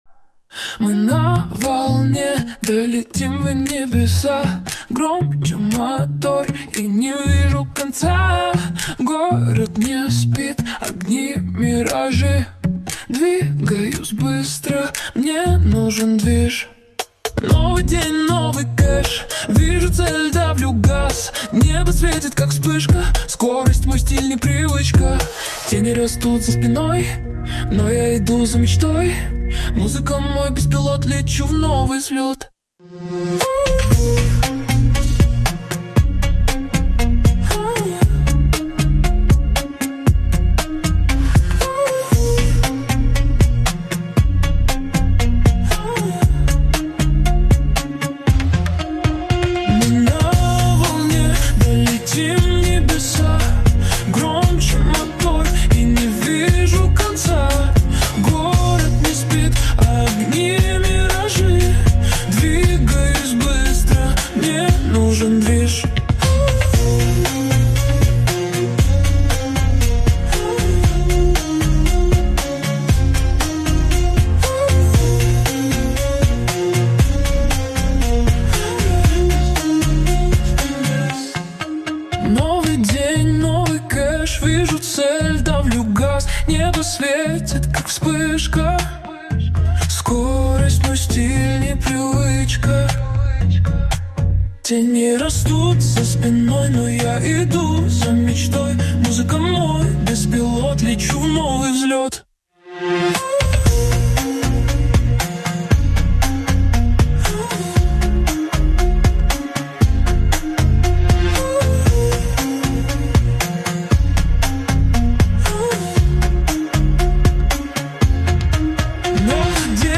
Жанр: Hip Hop